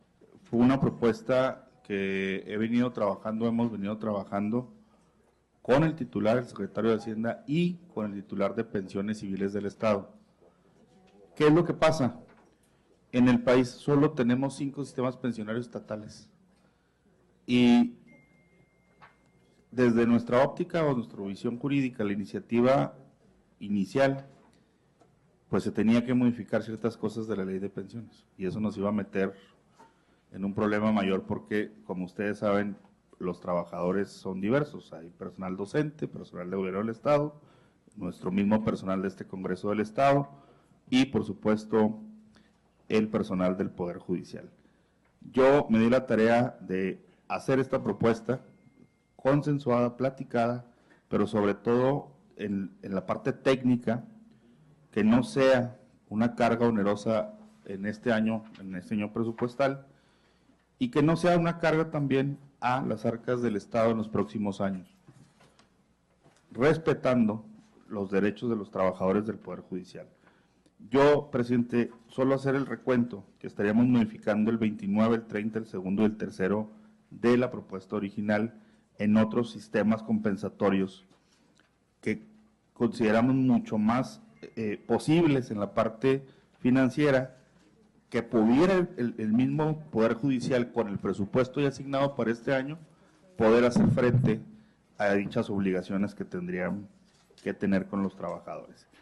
Durante la discusión, el diputado Alfredo Chávez, abordó aspectos financieros y propuso las modificaciones para evitar impactos en el presupuesto estatal, ante lo que mencionó, se reunió con la Secretaría de Hacienda, así como con Pensiones Civiles del Estado, quienes manejarían operativamente el recurso para el personal en retiro del Poder Judicial chihuahuense.